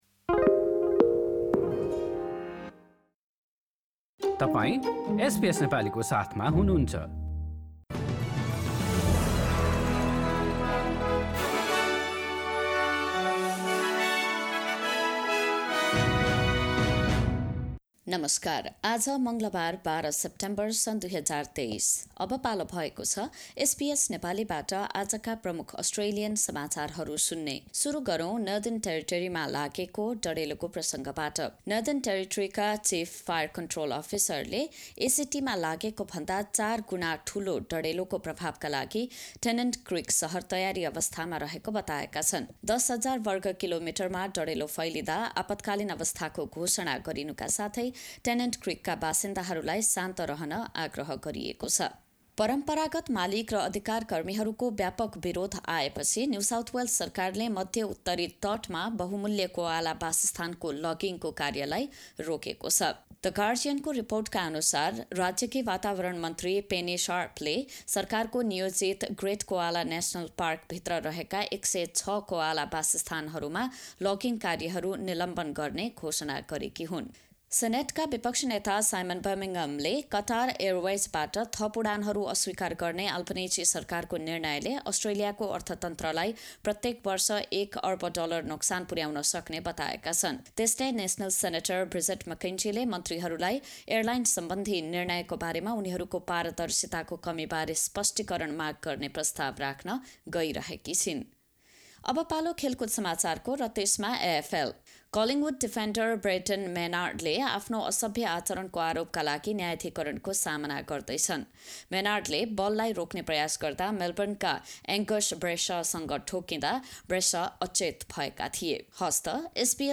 आजका प्रमुख अस्ट्रेलियाली समाचार छोटकरीमा सुन्नुहोस्।